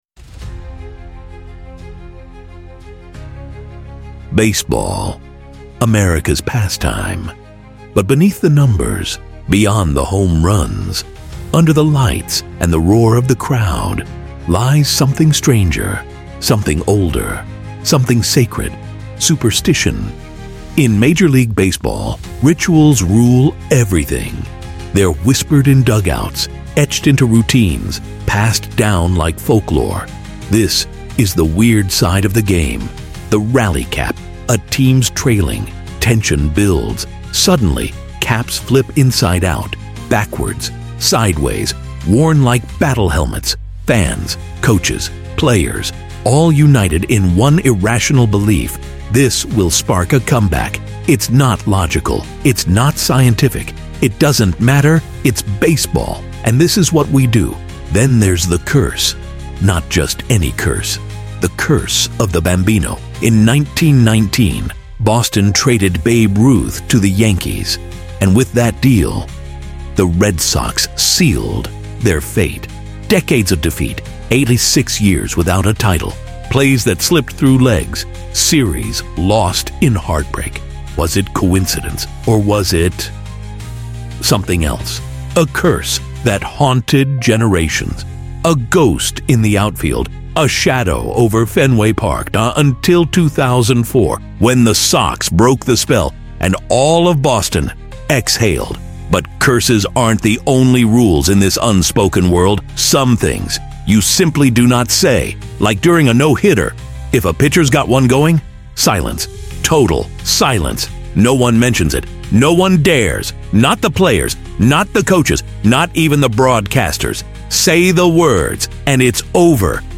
MUSIC PODCAST